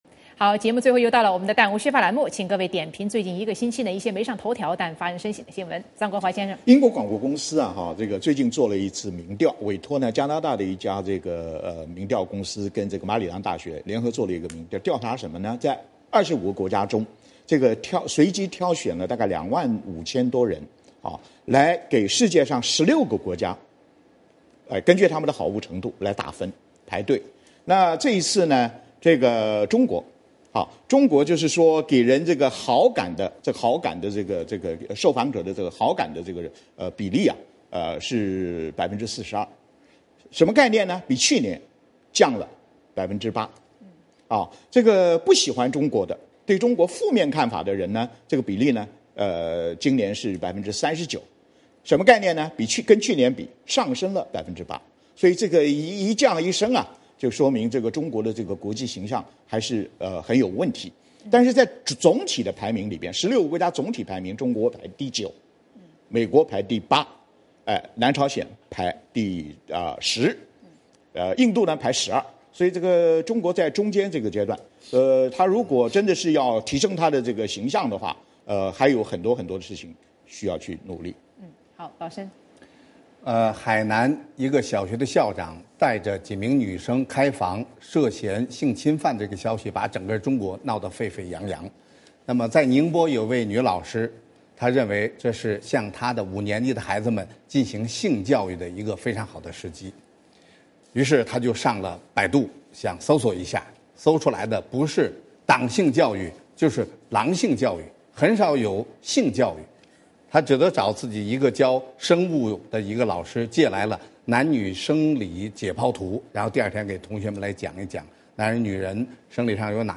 嘉宾点评最近一个星期一些没上头条，但发人深省的新闻: 中国印象，性教育，自由呼吸，十七条